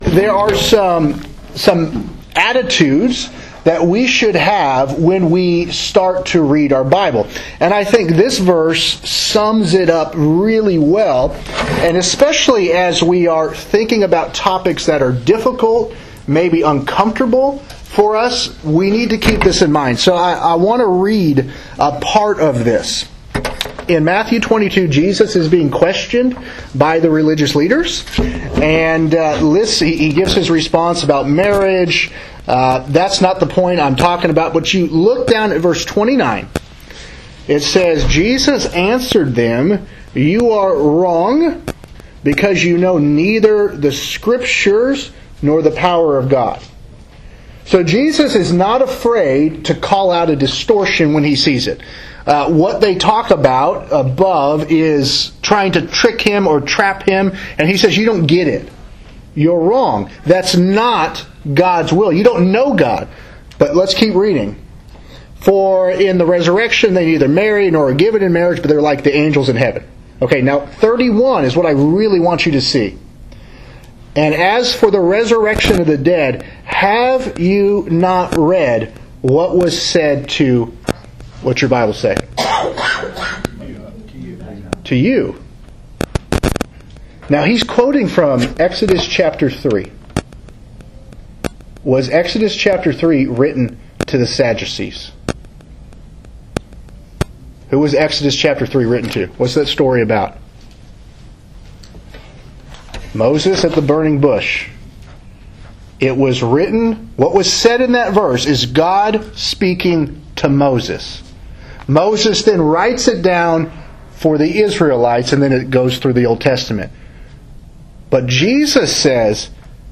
Sermons | Windsong church of Christ